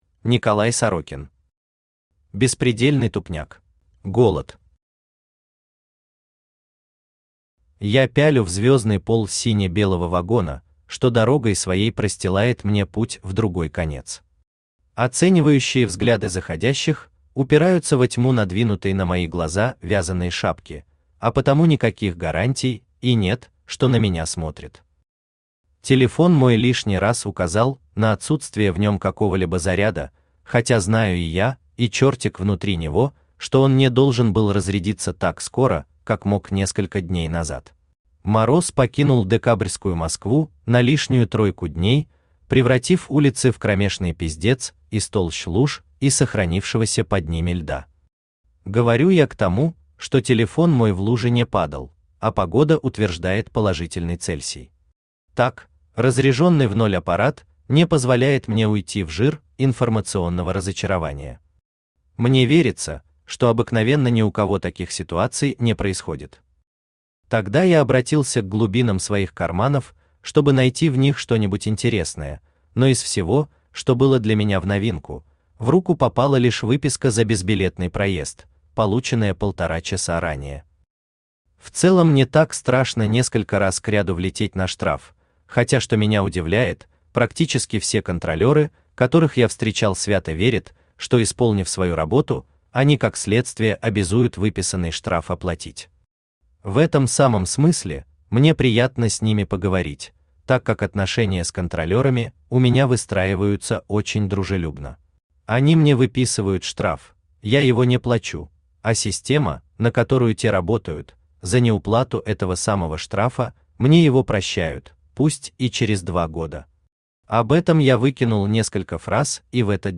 Аудиокнига Беспредельный тупняк | Библиотека аудиокниг
Aудиокнига Беспредельный тупняк Автор Николай Максимович Сорокин Читает аудиокнигу Авточтец ЛитРес.